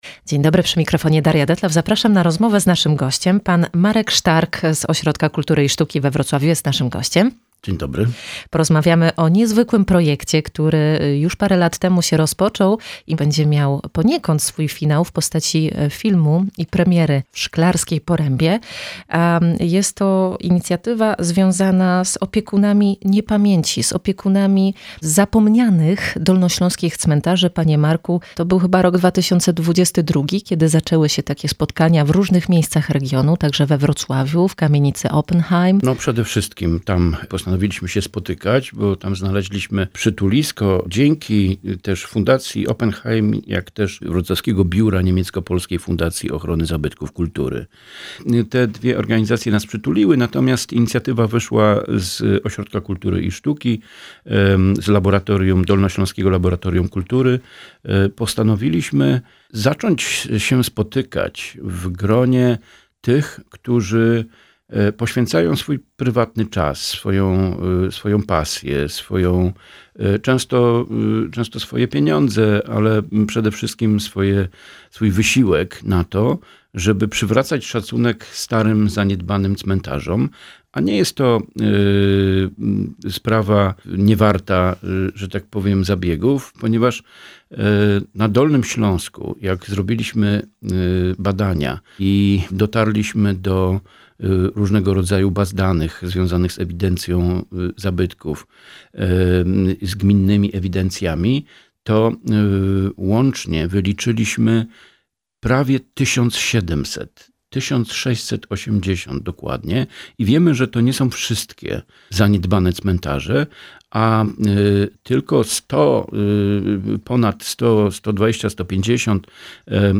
Cała rozmowa w piątek 5 grudnia w audycji „Przystanek Kultura” po godz. 10:10.